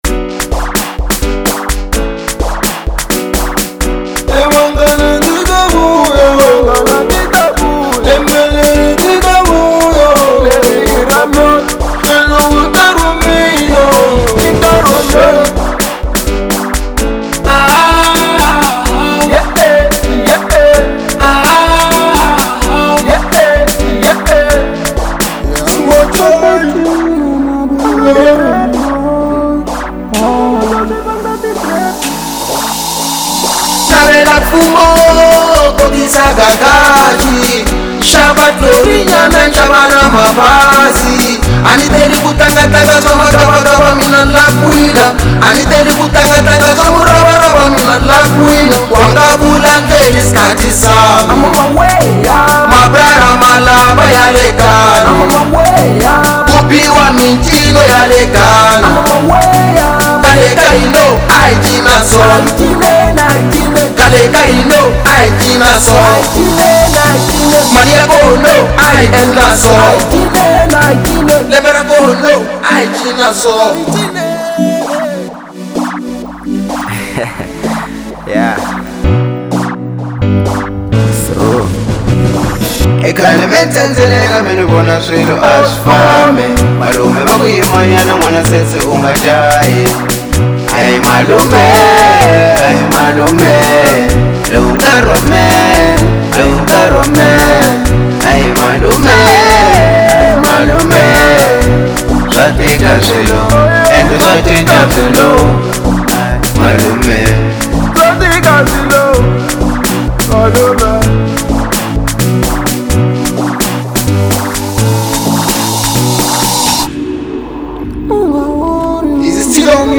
03:45 Genre : House Size